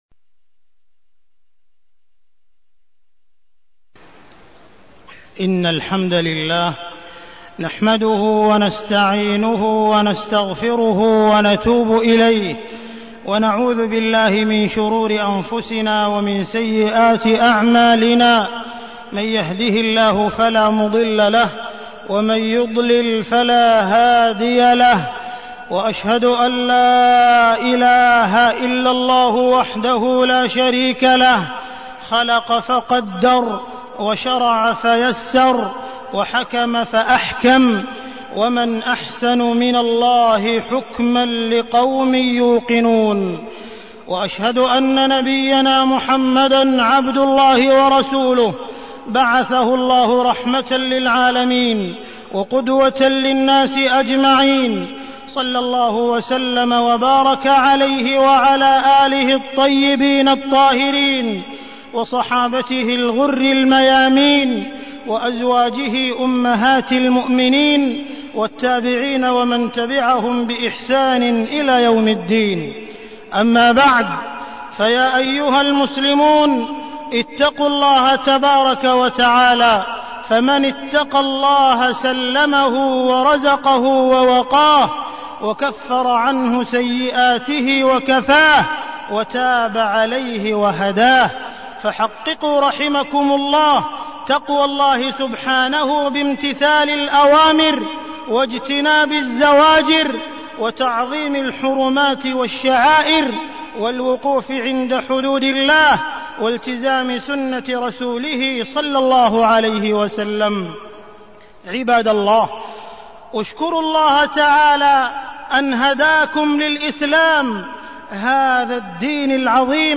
تاريخ النشر ١٣ جمادى الآخرة ١٤١٧ هـ المكان: المسجد الحرام الشيخ: معالي الشيخ أ.د. عبدالرحمن بن عبدالعزيز السديس معالي الشيخ أ.د. عبدالرحمن بن عبدالعزيز السديس الضرورات الخمس